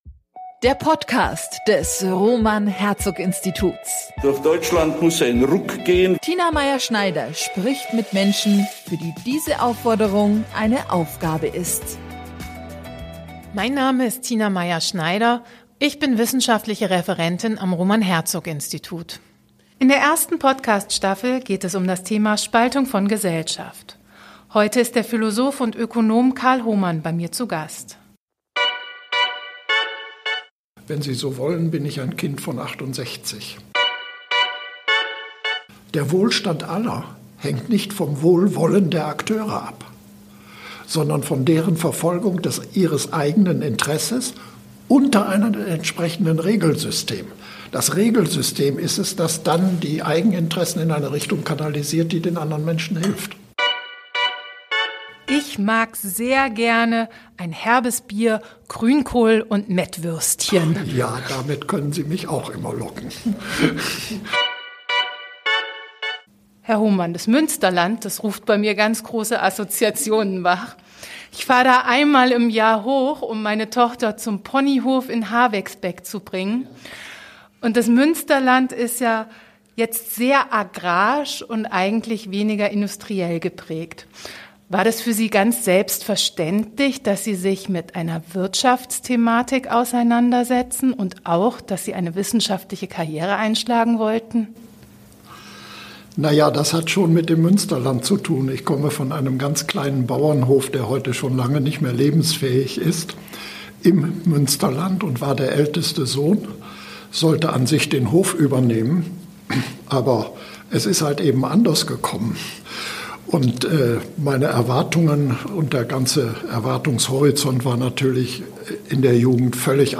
Der Podcast aus dem Roman Herzog Institut mit wechselnden Gästen.